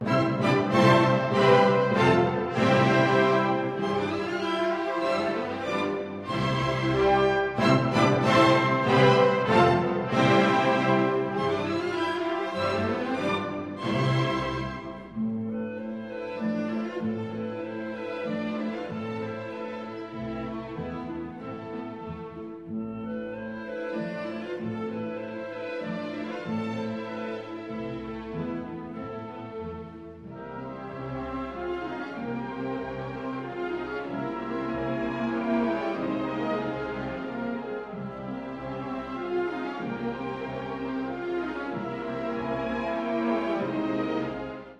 Lašské tance/1. Starodávný-1889 (Filharmonie Brno, dir. František Jílek, Supraphon 1993/2006)